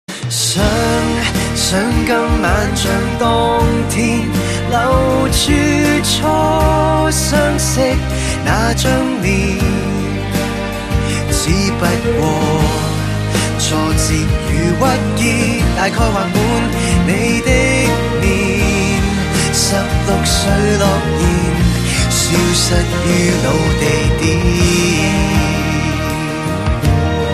M4R铃声, MP3铃声, 华语歌曲 83 首发日期：2018-05-14 22:15 星期一